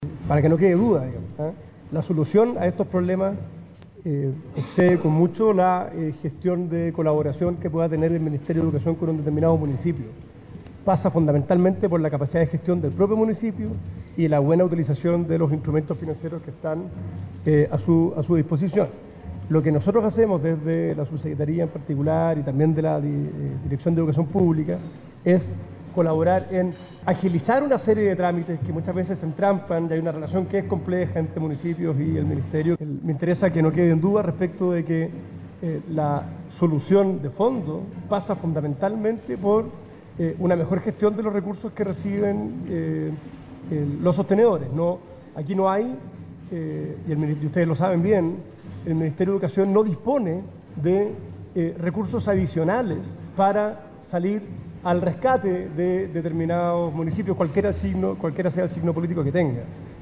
Dispares opiniones generó entre autoridades y parlamentarios la presentación que la delegación de Ancud realizó ante la comisión de Educación de la Cámara de Diputados, en la jornada del lunes, en Santiago.
La cartera de educación no puede salir “al rescate” de uno u otro municipio, dijo el subsecretario de Educación.